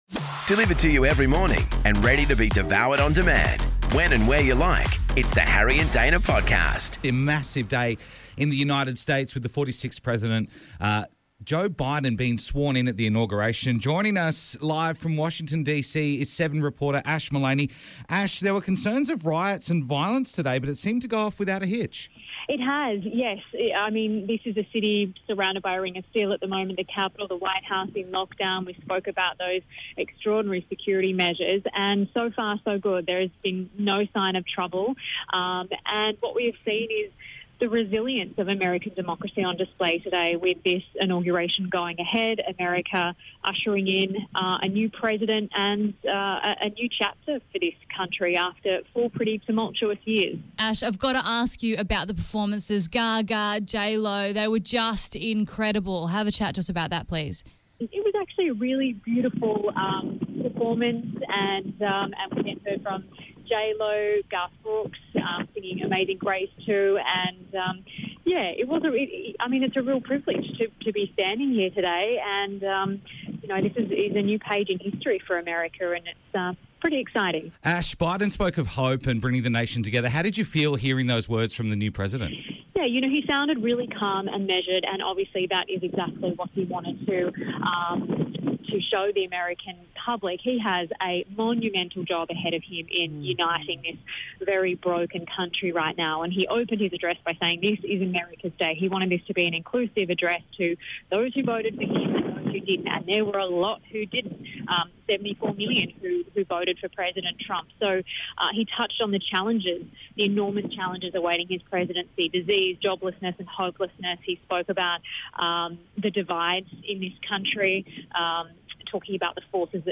Plus we chat with a local winemaker!